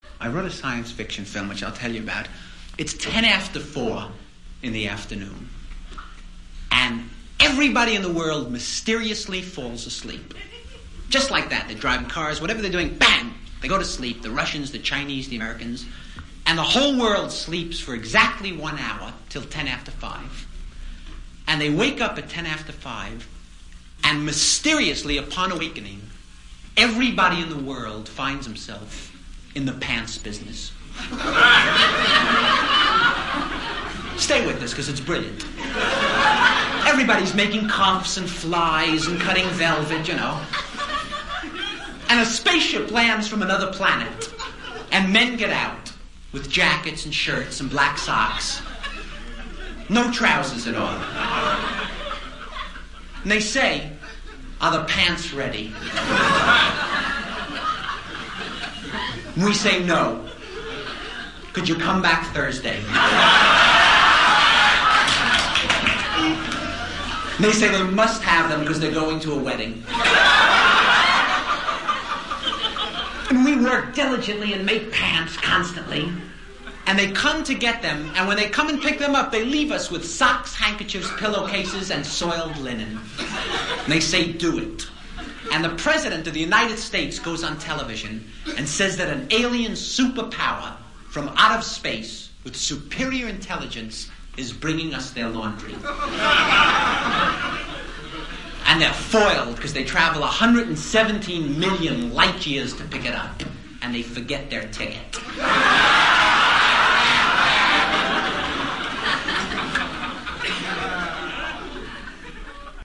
伍迪单口相声精选 第9期:科幻电影The Science Fiction Film 听力文件下载—在线英语听力室